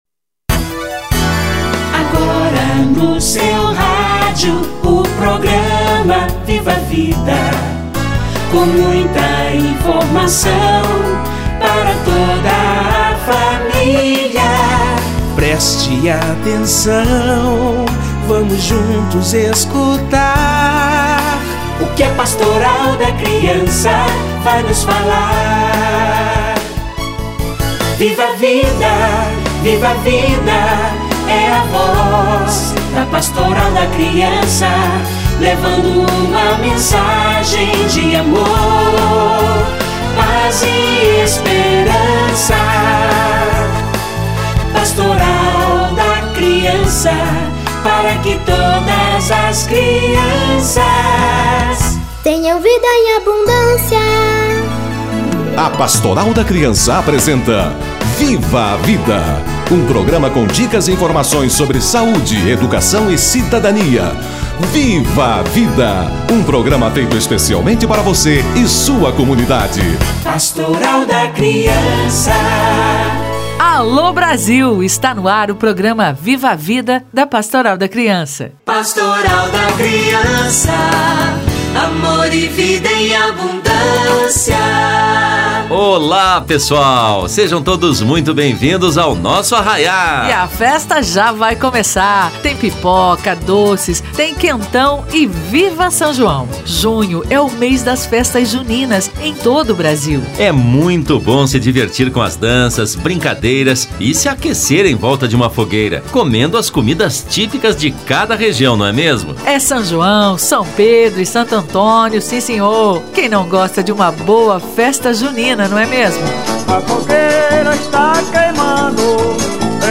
Festas juninas e julinas - Entrevista